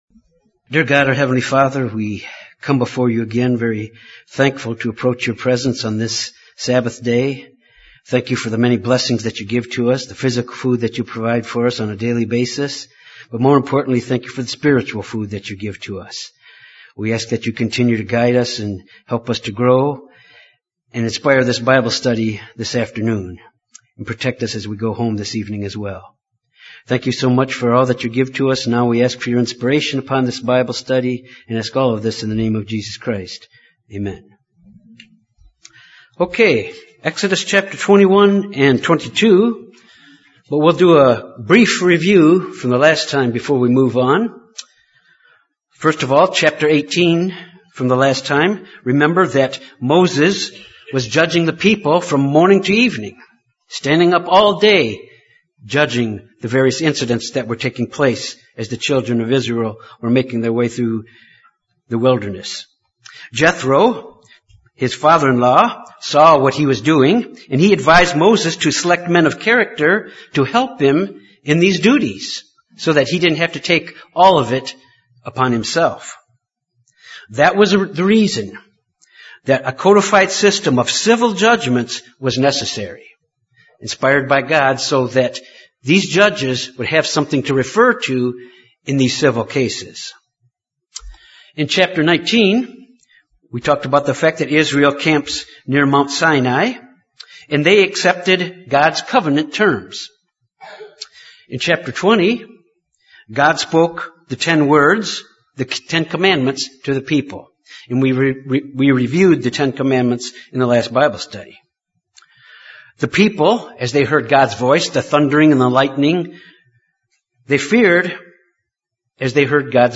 This Bible Study examined the application of the Ten Commandments in the from of civil statutes and judgments. These statues and judgments provided guidance to future generations with precedence to serve as guidance for their decisions.